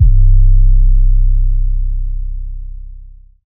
DDW 808 2.wav